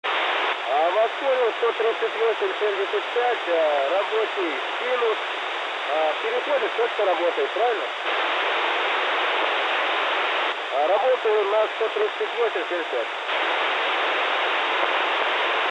sinus.mp3